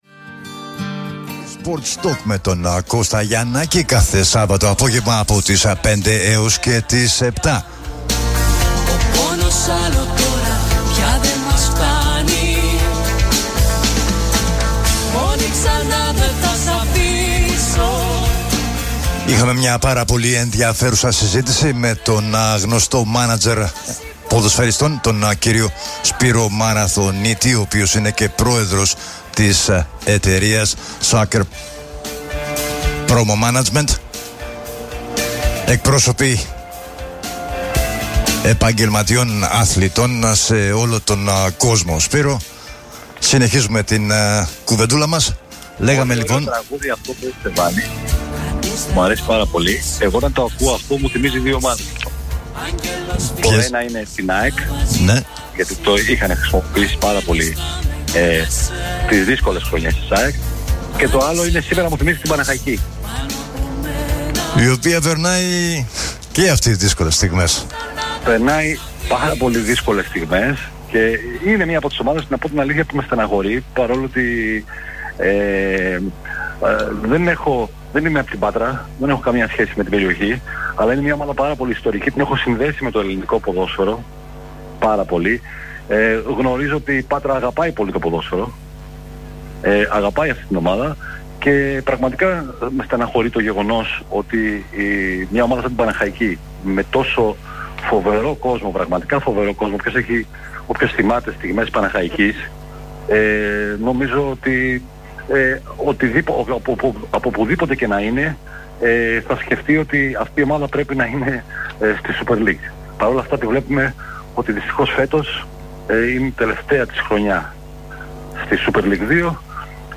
Ακούστε απόσπασμα της χθεσινής συνέντευξης: